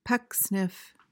PRONUNCIATION: (PEK-snif) MEANING: noun: A hypocritical person who pretends to have high moral principles.